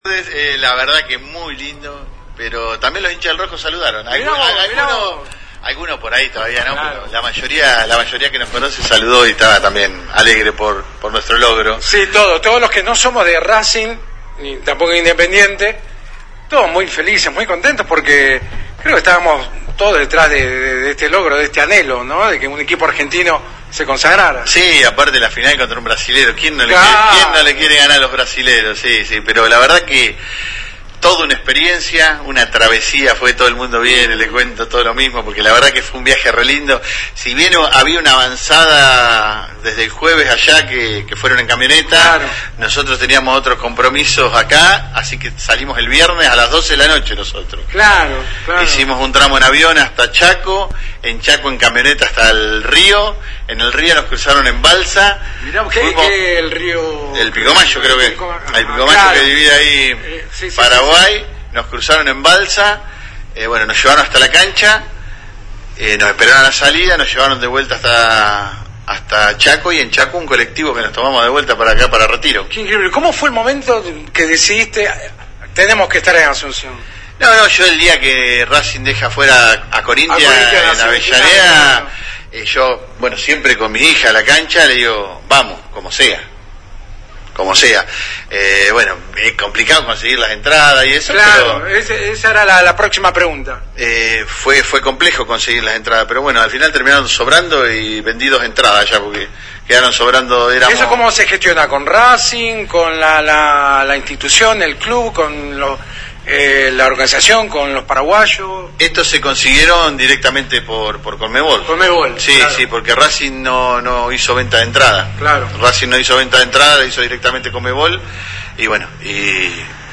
(audio y galería de imágenes) Un grupo de hinchas de la academia que viven en Las Flores hablaron este viernes con FM Alpha luego de la consagración del equipo de Gustavo Costas. Hicieron casi 1.500 kilómetros hasta Asunción (Paraguay) para ver en vivo la final de la Copa Sudamericana que consagró al equipo argentino luego de la victoria 3 a 1 a Cruzeiro (Brasil).